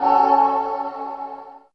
64 GUIT 3 -R.wav